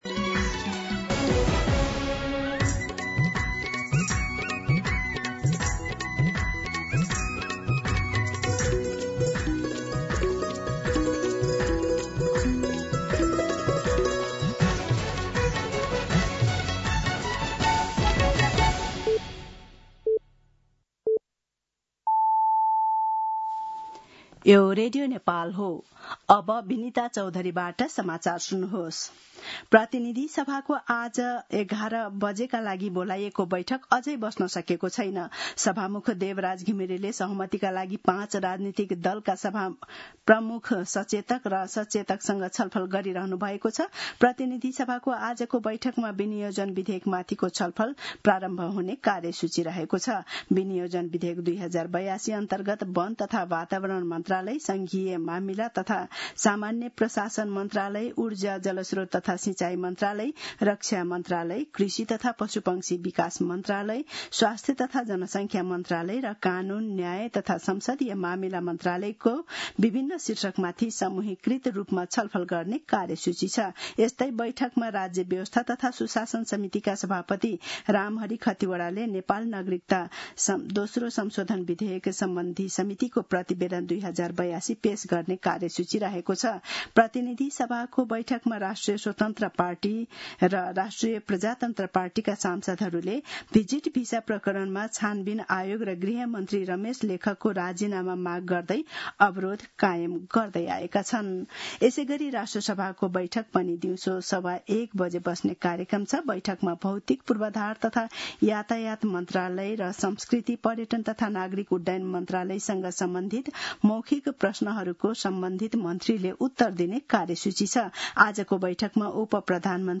मध्यान्ह १२ बजेको नेपाली समाचार : ५ असार , २०८२
12-pm-Nepali-news.mp3